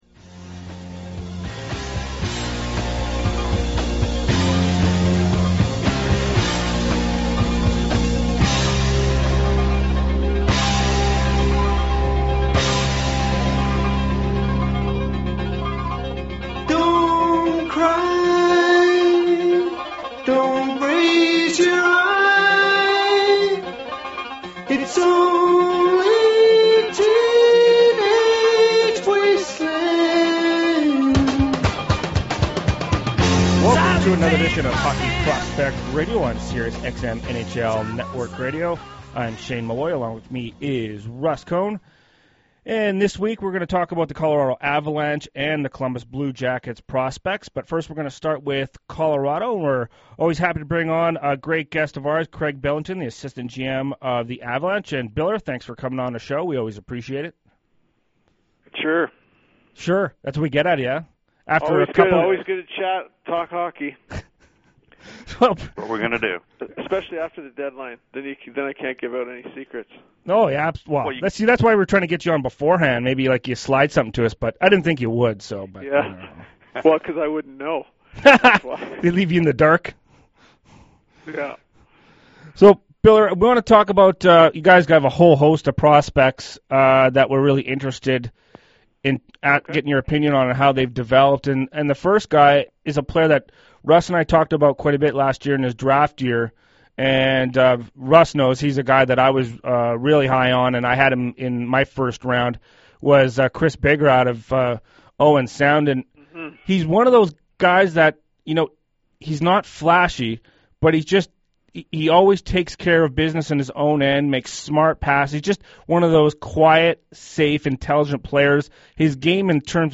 Colorado Avalanche Assistant General Manager, Craig Billington joined NHL Radio’s Prospect show